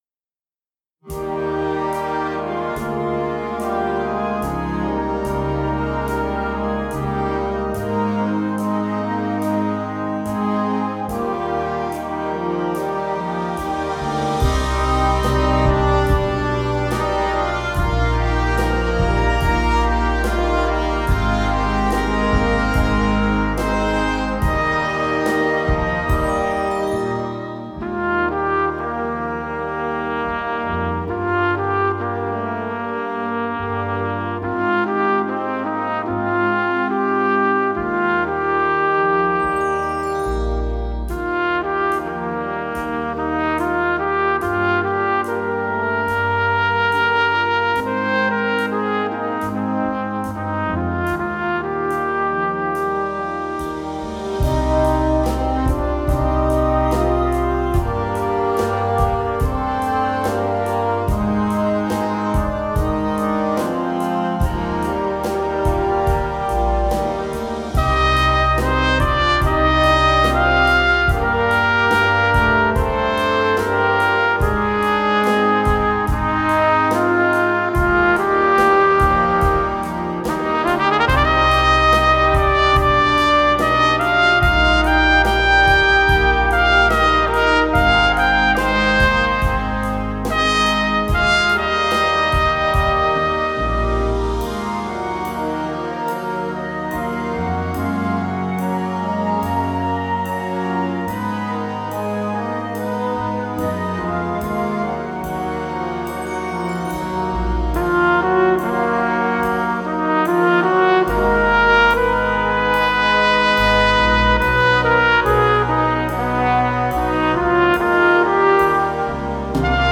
Noten für Blasorchester.
Audio clip band + trumpet
Dieses langsame Stück für Solo-Trompete